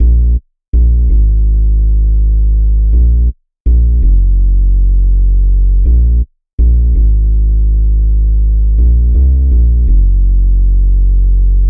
冲动的合成器低音
Tag: 83 bpm Hip Hop Loops Bass Synth Loops 1.99 MB wav Key : Am Cubase